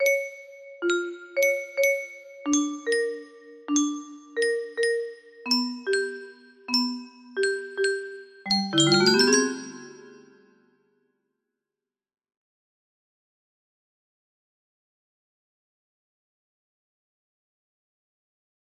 The music box part